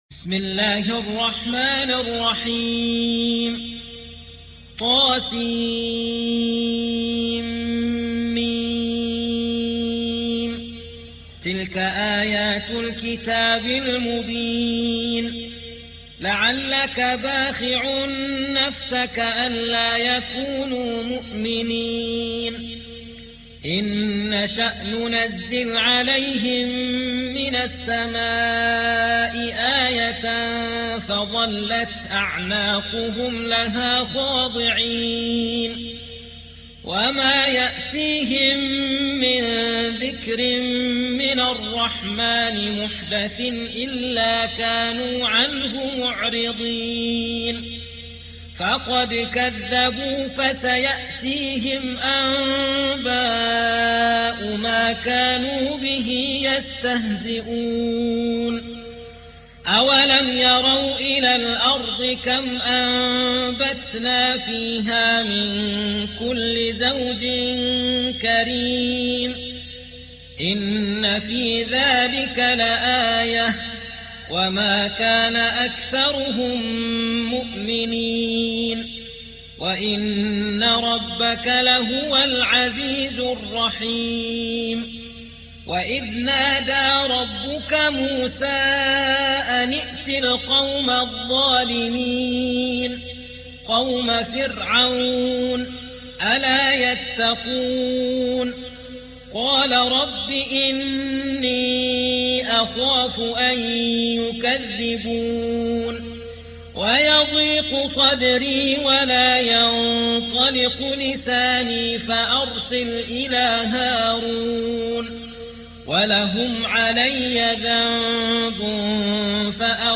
26. سورة الشعراء / القارئ